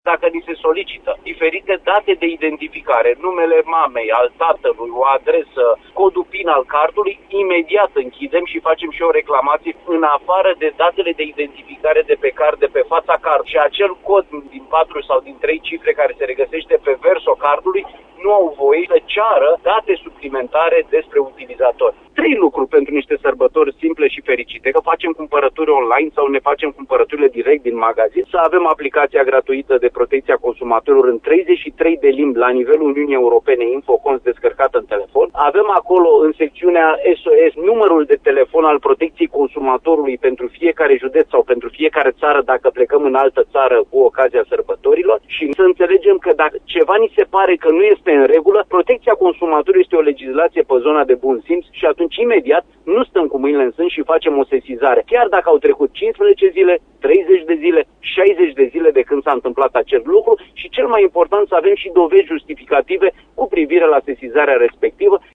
invitat joi, 19 decembrie, la Radio Cluj